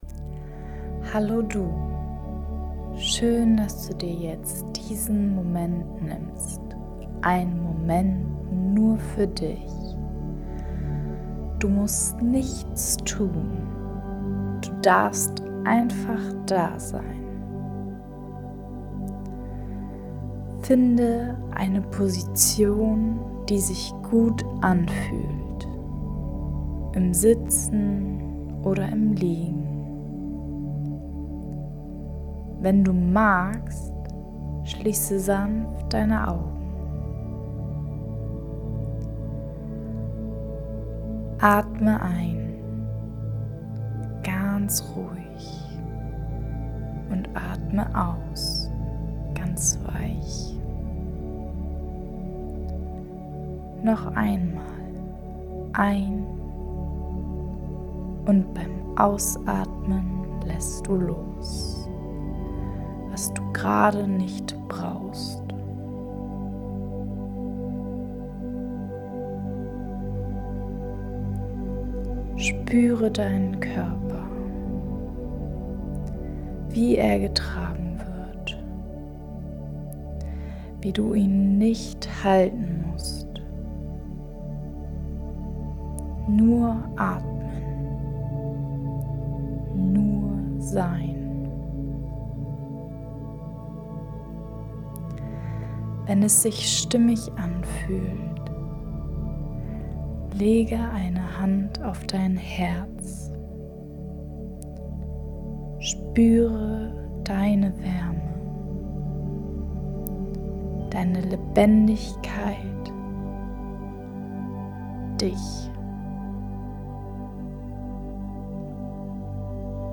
📄 Freebie öffnen (PDF) Dein Bonus-Audio: Mini-Atemreise (ca. 5 Min.) Diese geführte Atemreise hilft dir, dein Nervensystem zu beruhigen und sanft bei dir selbst anzukommen - ruhig, weich, verbunden.